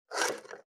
470,包丁,厨房,台所,野菜切る,咀嚼音,ナイフ,
効果音